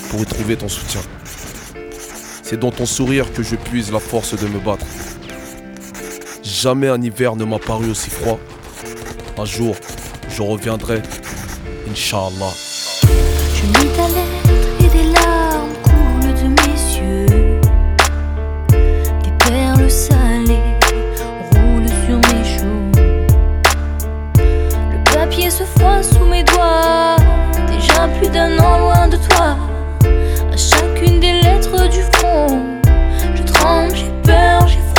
Жанр: Соул / R&b